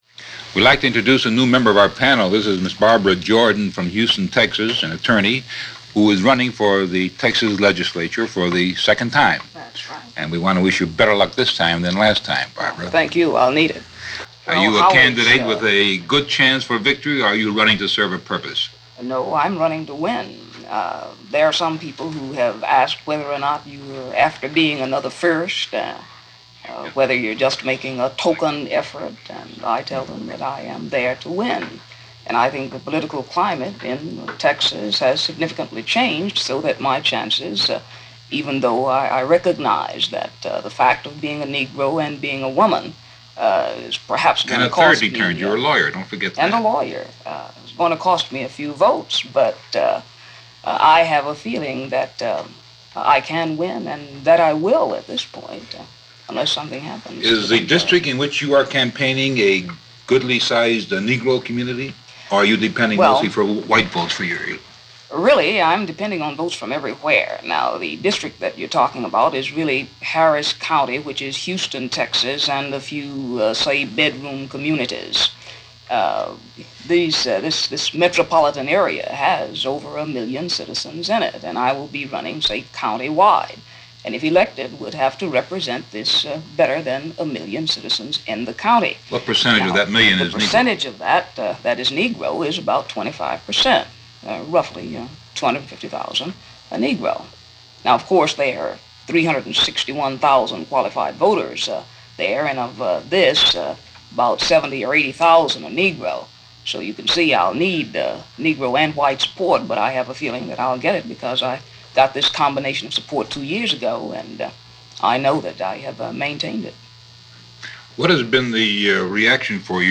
A word Or Two From Barbara Jordan - an interview with Irv Kupcinet as it was broadcast on March 7, 1964 - Past Daily Reference Room.